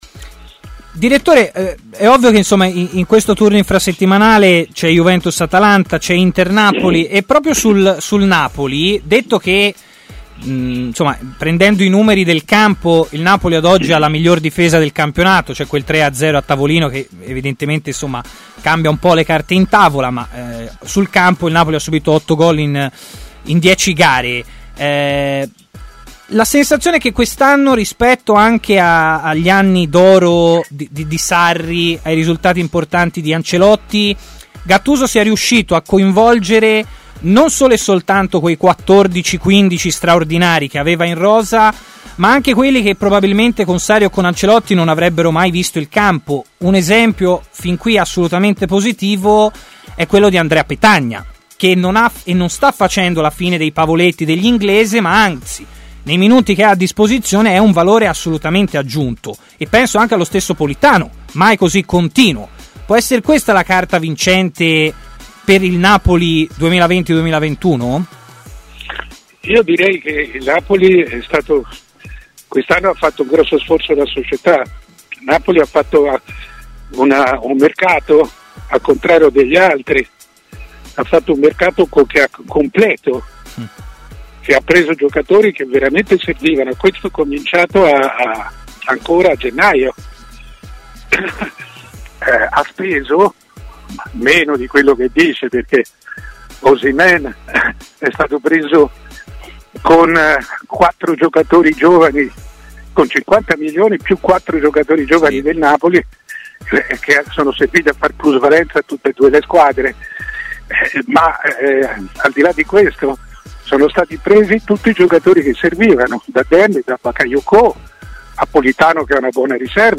Il direttore Mario Sconcerti è intervenuto a Stadio Aperto, trasmissione di TMW Radio, iniziando dal caso Papu Gomez